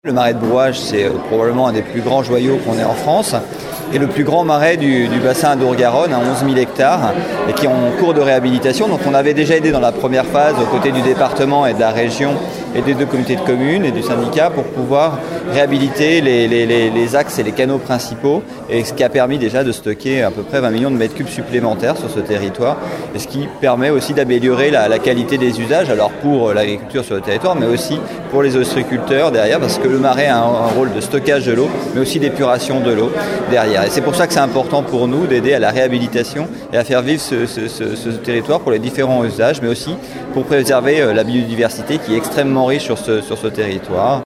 Lors de la signature hier.